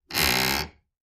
Kitchen cabinet door hinge squeaks when opened and closed. Open, Close Screech, Door